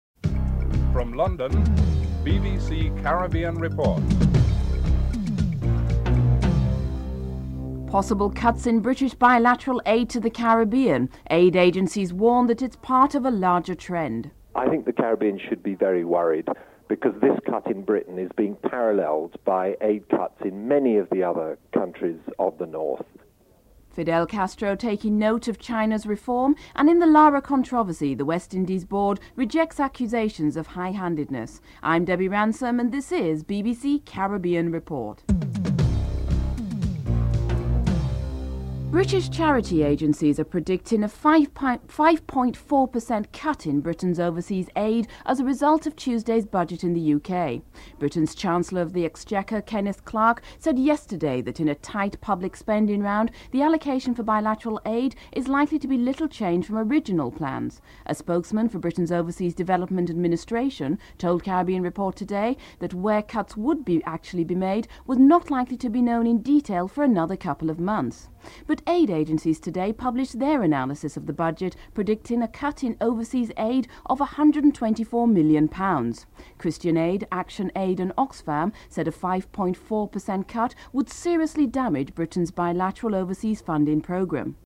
The British Broadcasting Corporation
9. Recap of top stories (14:40-15:12)